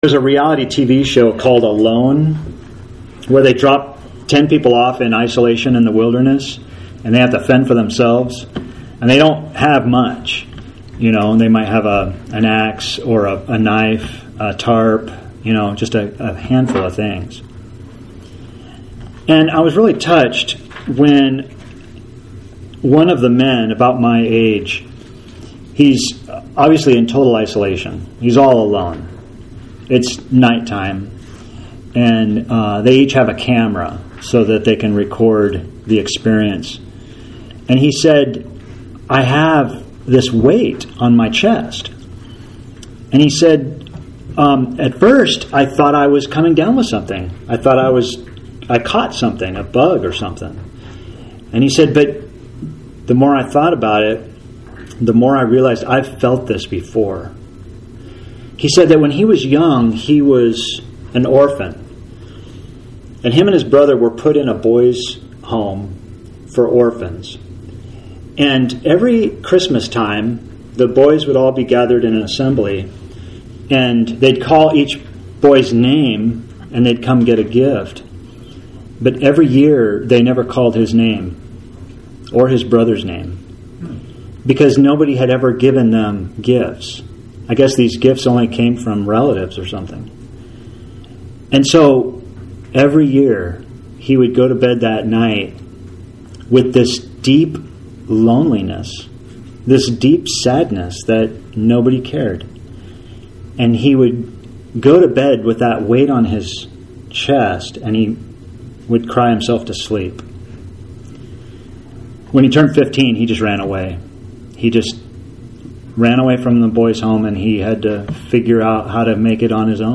Sermon for June 21, 2020 – TEXT PDF | AUDIO